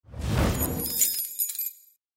n1_ui_sound_box_open.mp3